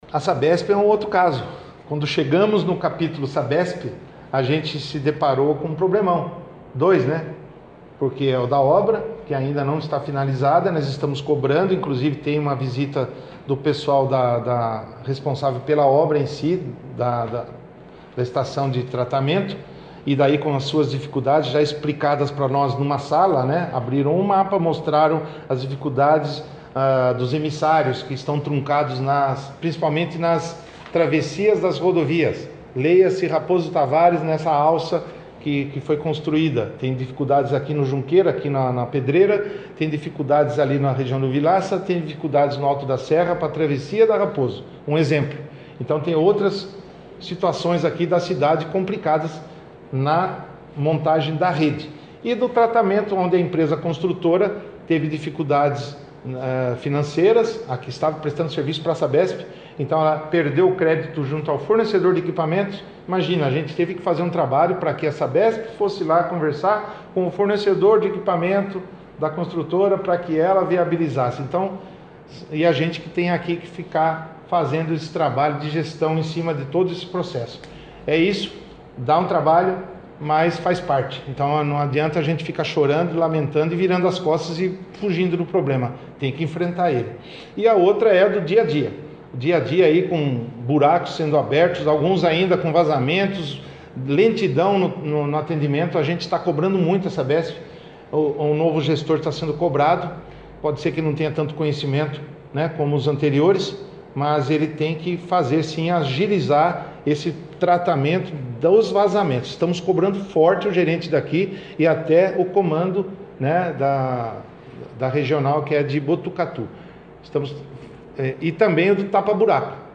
OUÇA O PREFEITO CLAUDIO GÓES